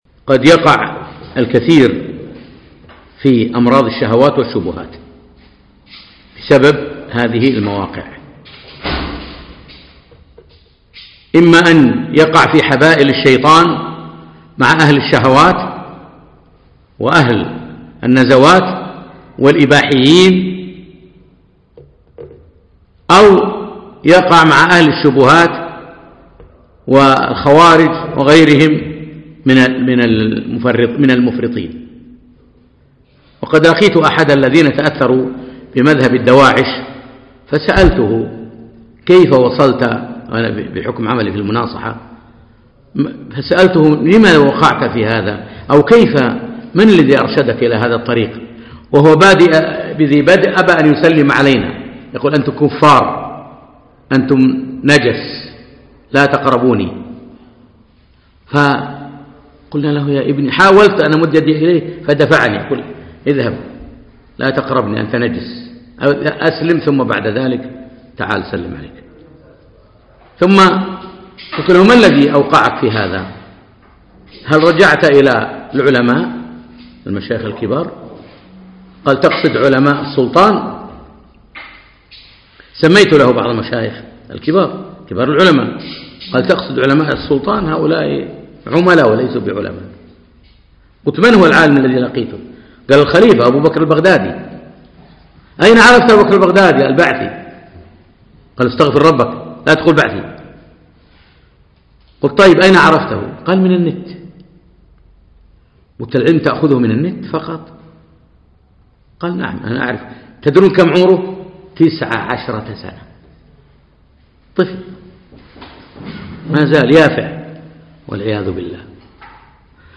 مقتطف من محاضرة ضوابط التعامل مع وسائل التواصل